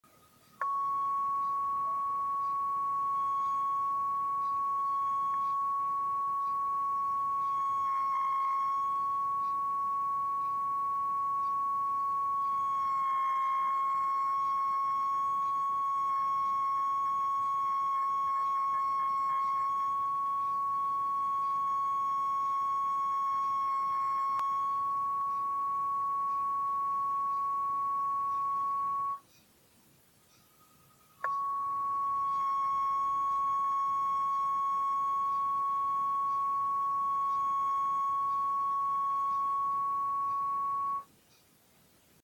А теперь треки. Записывал сотиком, около пьезика.
Иногда посылки длятся несколько секунд и даже пробивается модуляция голосом